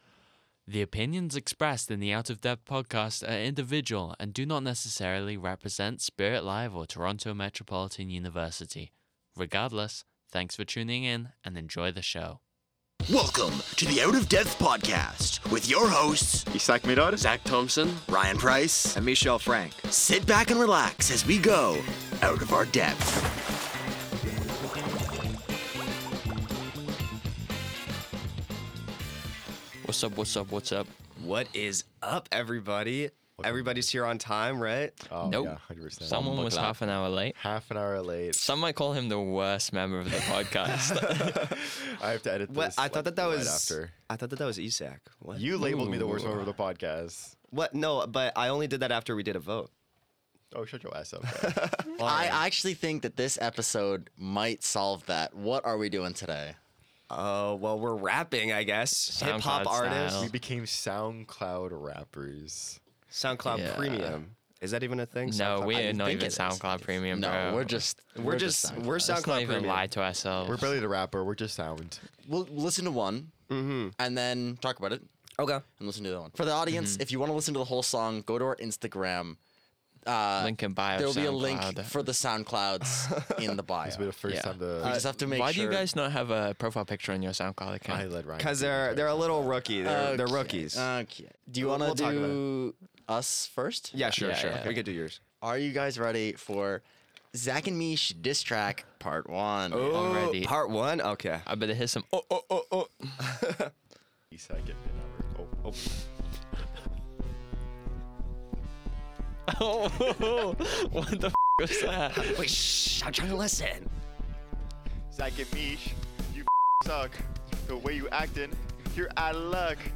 We spend every week doing a new challenge to go 'out of our depth' to try things you normally wouldn't, in order to bring a new perspective to the Comedy genre.